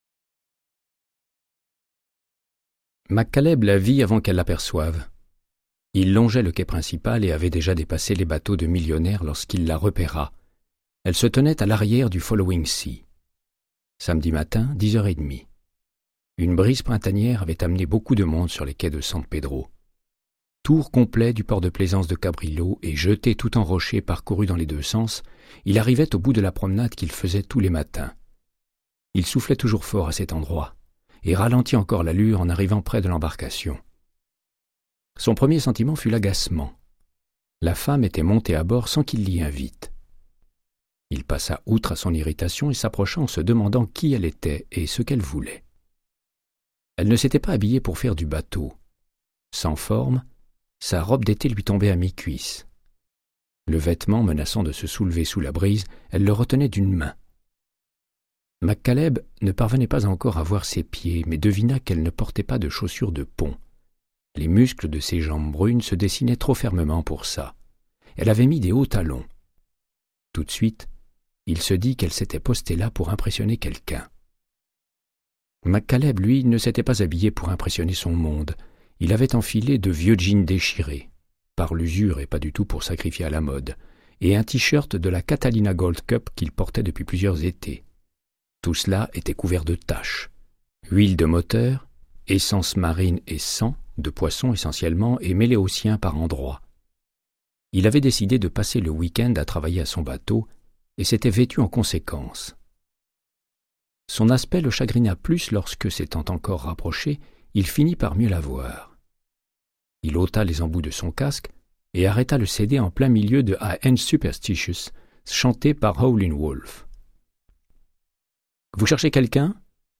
Audiobook = Créance de sang, de Michael Connellly - 02